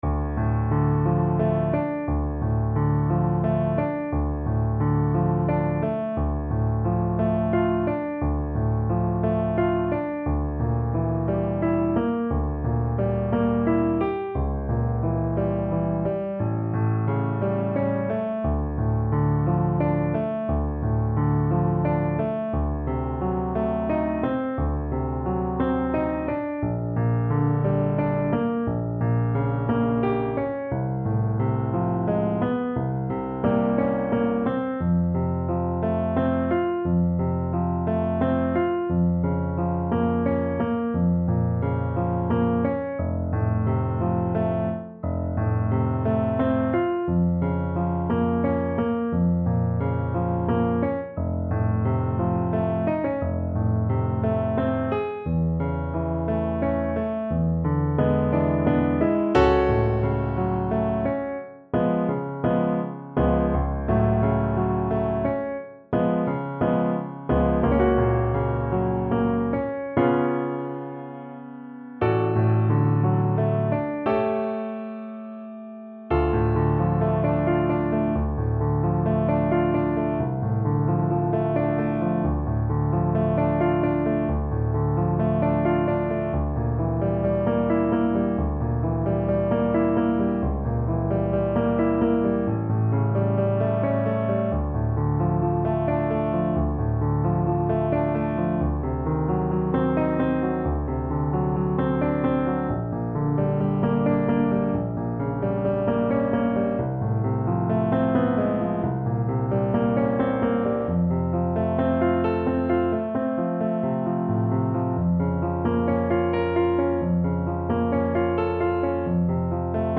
Play (or use space bar on your keyboard) Pause Music Playalong - Piano Accompaniment Playalong Band Accompaniment not yet available transpose reset tempo print settings full screen
D minor (Sounding Pitch) E minor (Clarinet in Bb) (View more D minor Music for Clarinet )
~ = 88 Malinconico espressivo
3/4 (View more 3/4 Music)
Classical (View more Classical Clarinet Music)